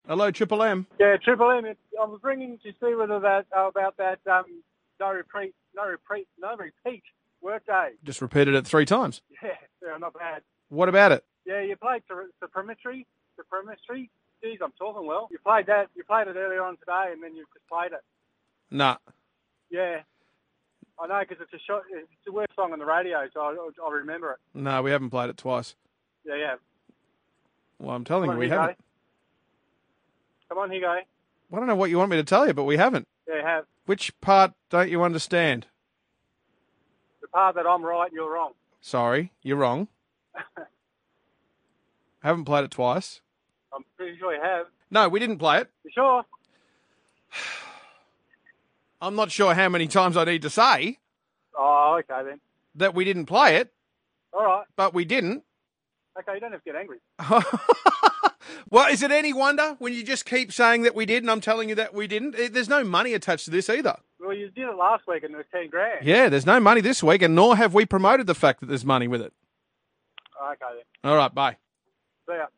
A typical 'No Repeat Workday' caller